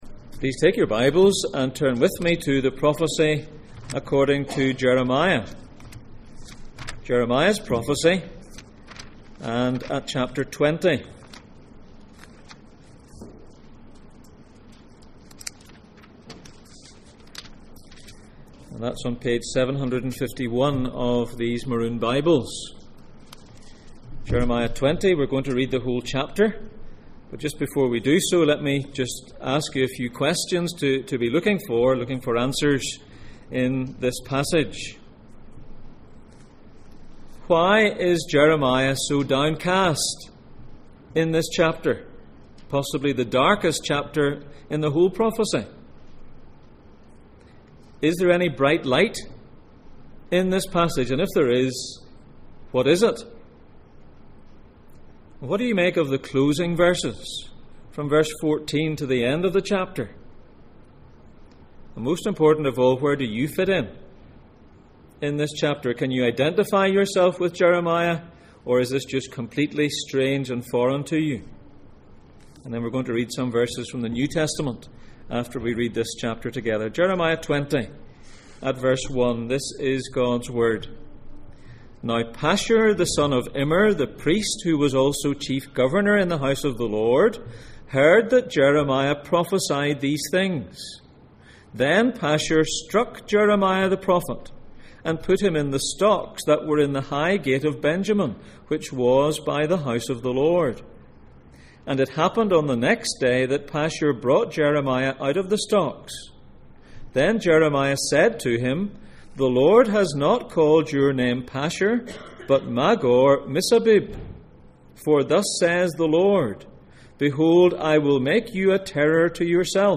Preacher
Passage: Jeremiah 20:1-18, Mark 4:35-41 Service Type: Sunday Morning